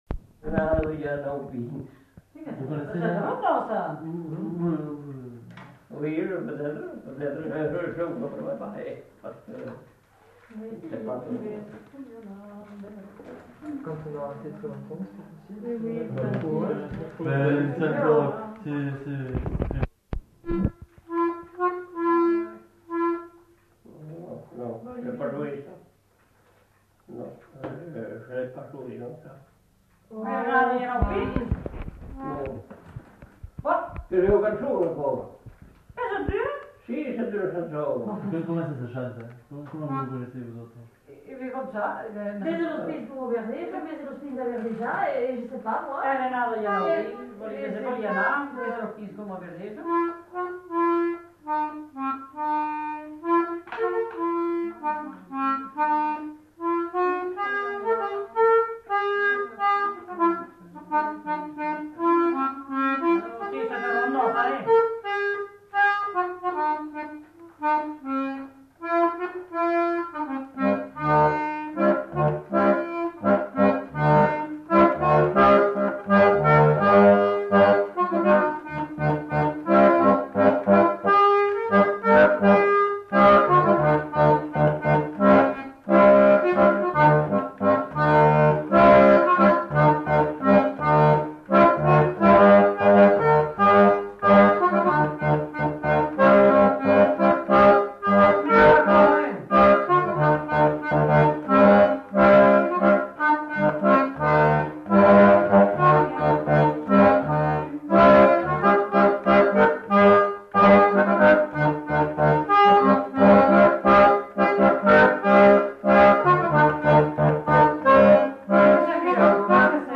Lieu : Sainte-Maure-de-Peyriac
Genre : morceau instrumental
Instrument de musique : accordéon diatonique
Danse : rondeau
Notes consultables : En début de séquence, quelques paroles de la chanson sont données par deux dames.